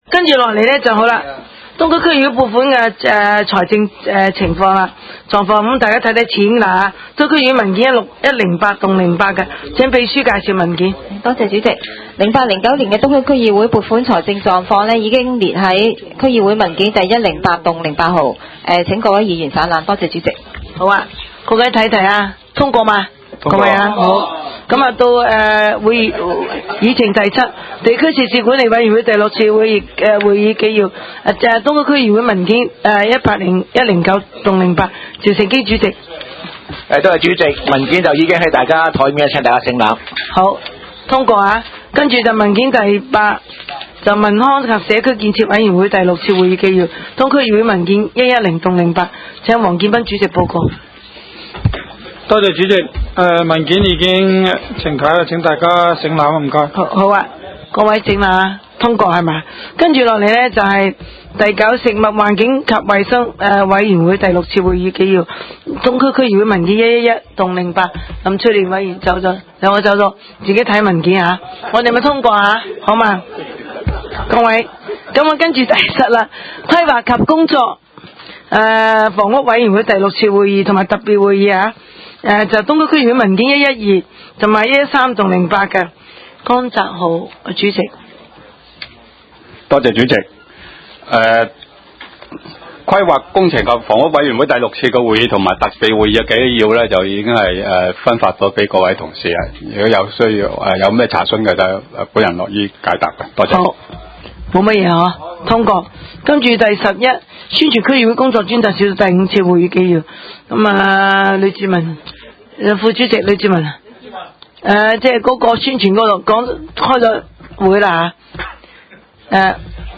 東區區議會會議室